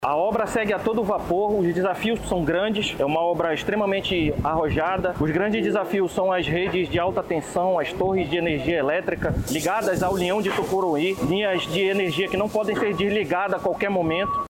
O subsecretário Municipal de Infraestrutura, Efraim Aragão, também acompanhou a vistoria e destacou os desafios técnicos enfrentados na execução do projeto.